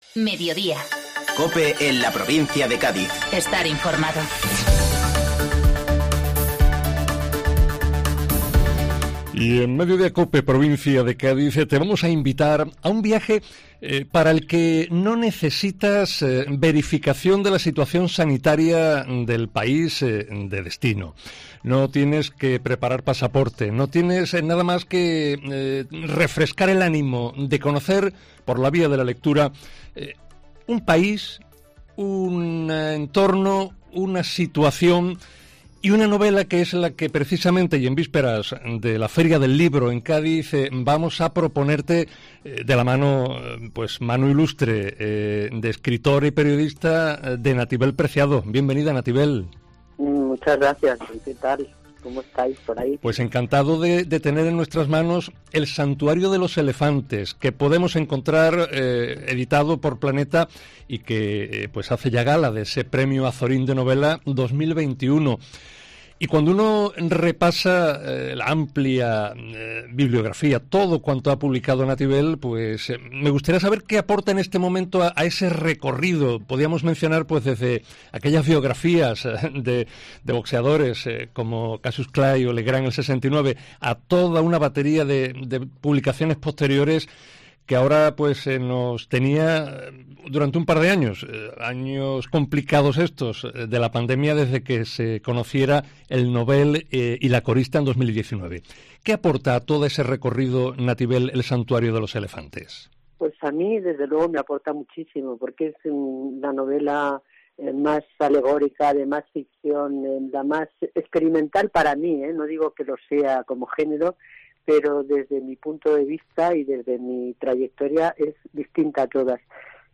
En Mediodía COPE Provincia de Cádiz no aguardamos a que Nativel Preciado llegue a Cádiz y nos anticipamos por medio de una entrevista en la que descubrir la novela de quien fuera autora, entre otras, de 'El Nobel y la corista' (2019).